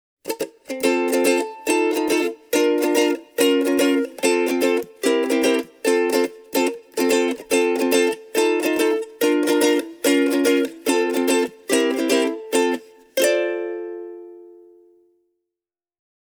The Flight NUS310 is an affordable soprano uke, and it even comes in its own stylish tweed bag.
The NUS310’s body is clearly wider than on most sopranos, in order to add a smidgen more bottom end to proceedings.
In my opinion the Flight NUS310 is a cool little soprano uke, offering a vintage-type sound and good intonation.